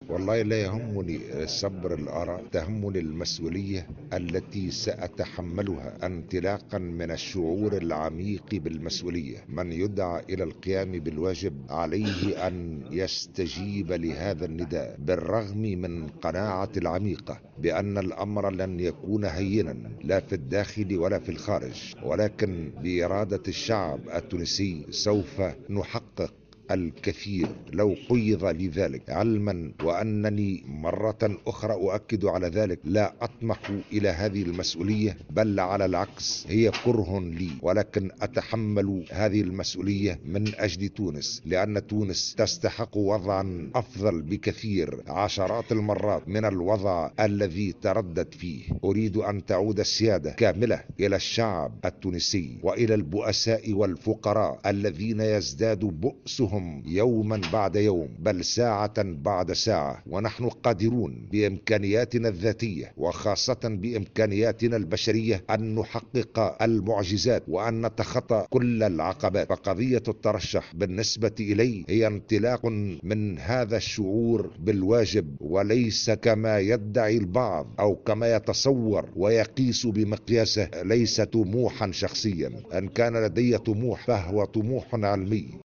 واعتبر في تصريح لمراسلة "الجوهرة اف أم"، على هامش ملتقى فكري في توزر، هذا الترشّح كرها له، مشددا على أهمية المسؤولية التي سيتحملها، مشيرا إلى ان الأمر غير هيّن، وفق تعبيره.